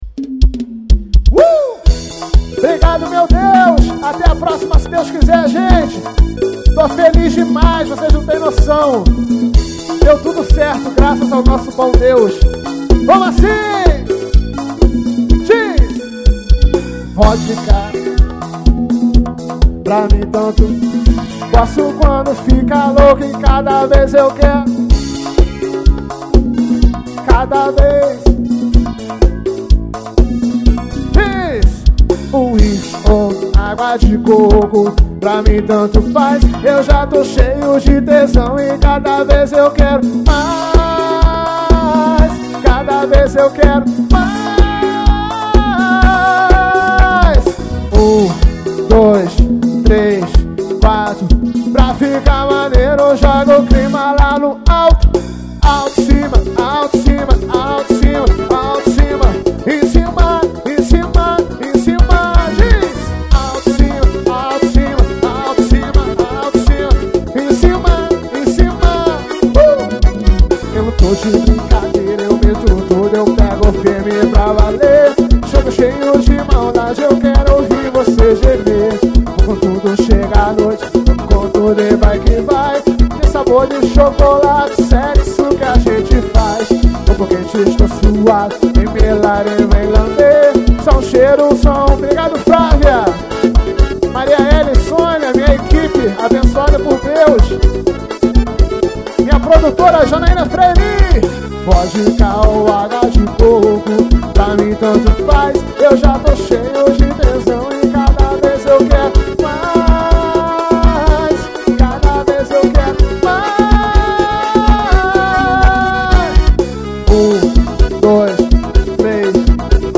Festa da estação 2013.